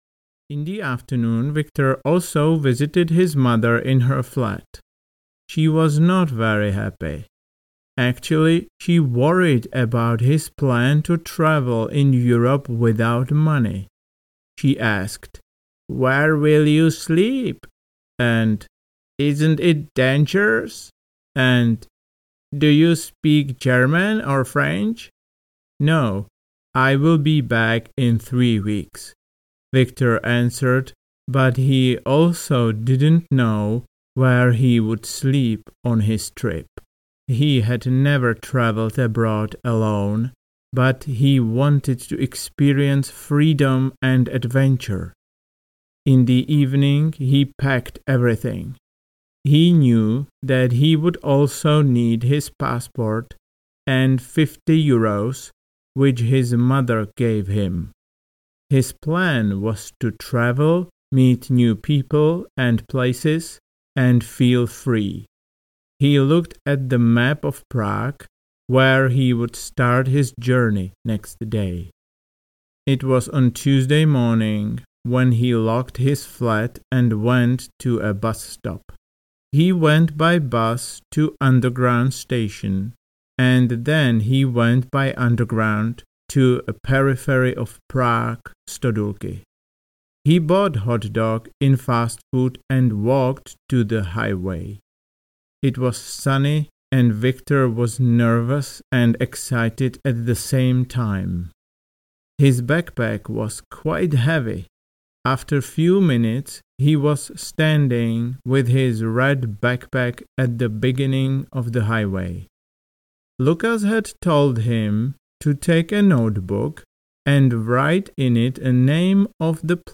Audiokniha
Příběh v pomalejším tempu (30 minut), pak 300 otázek a odpovědí o příběhu (15 minut) a nakonec znovu celý příběh v rychlejším tempu (20 minut).